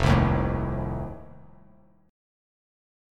F7 Chord
Listen to F7 strummed